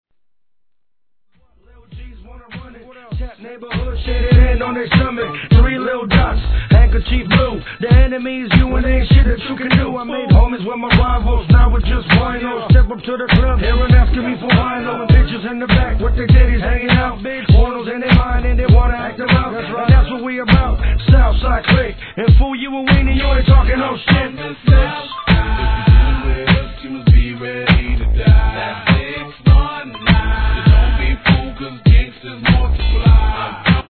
1. HIP HOP/R&B